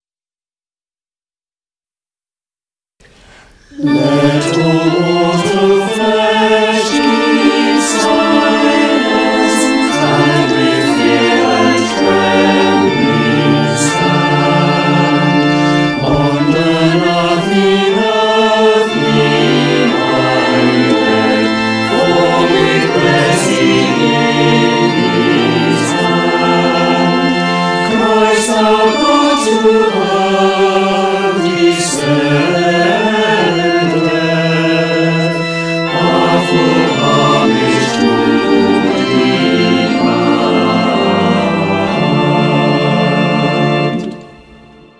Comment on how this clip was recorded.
Over the coming weeks we will be recording our individual parts of choral music, to be assembled into a virtual chorus and we will post the results here. This is the first trial session: